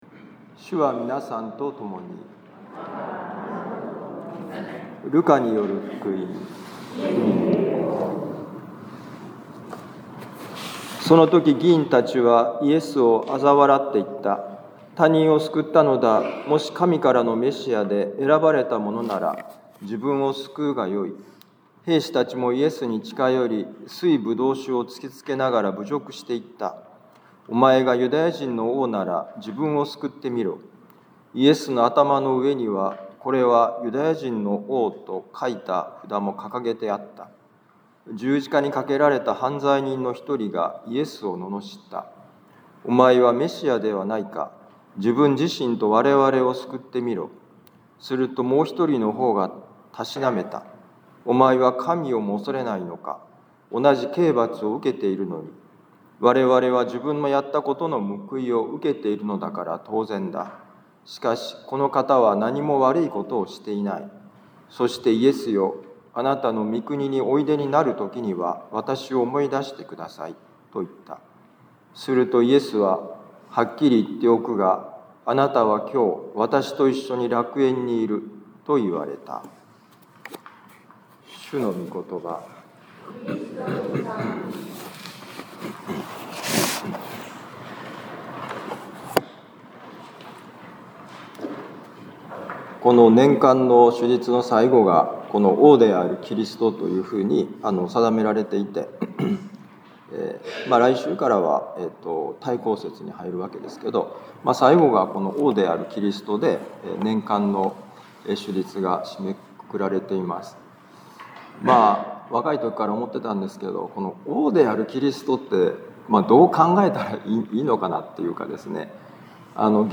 【ミサ説教】
ルカ福音書23章35-43節「王の力」2025年11月23日王であるキリスト カトリック防符教会ミサ